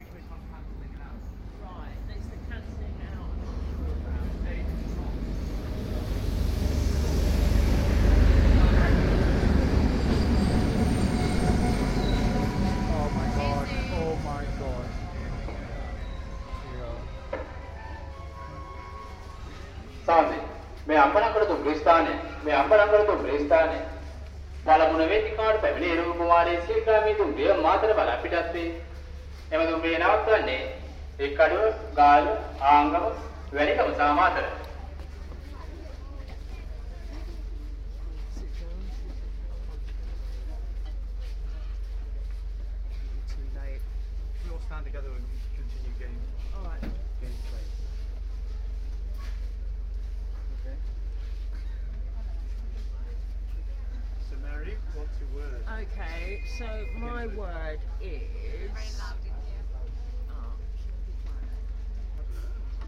Ambalangoda train station announcements